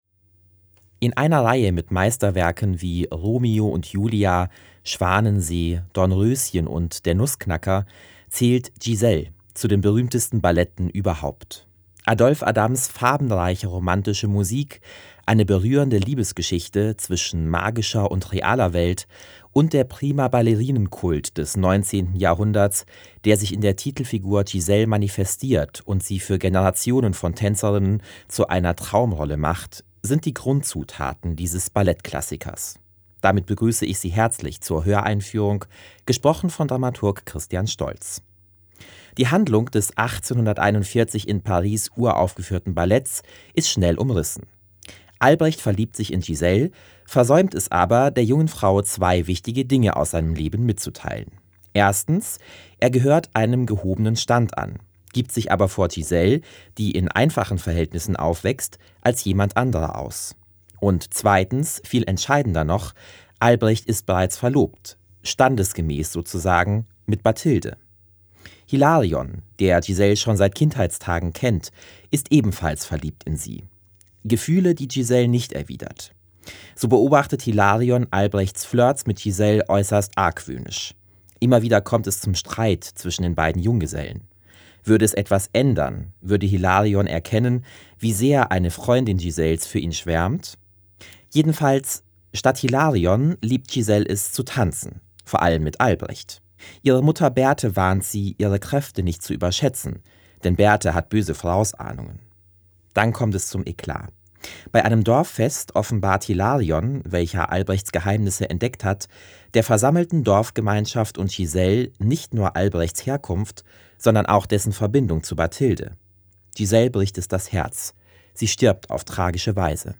Höreinführung Giselle.mp3